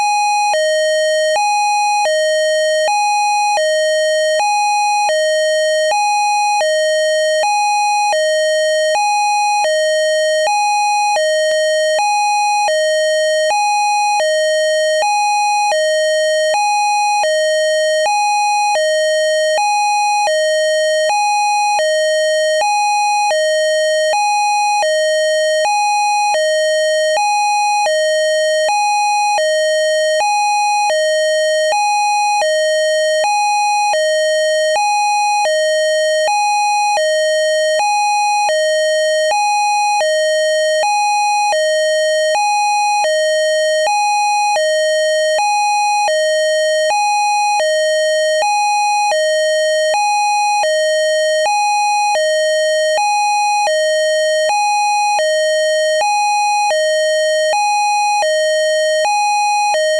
hi-lo tones.
Siren Tones
hilo.wav